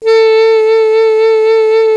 55af-sax07-A3.aif